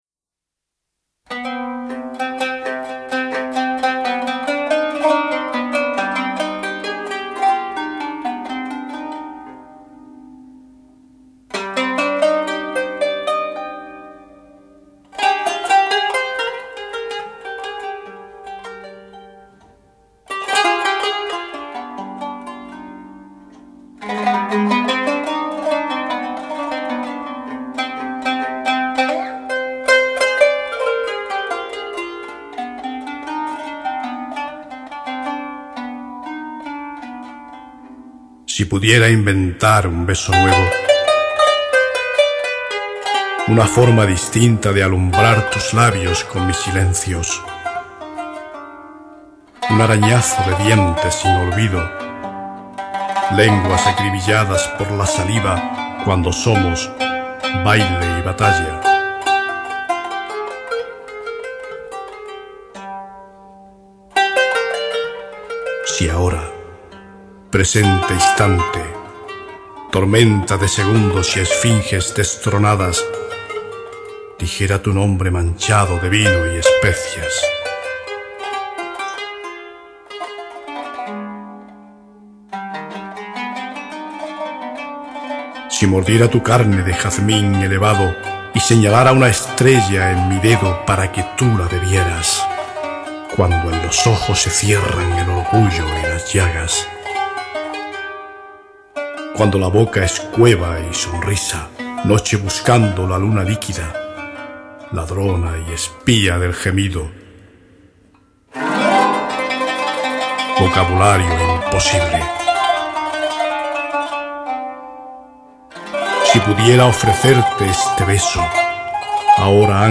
el Ney (flauta de caña).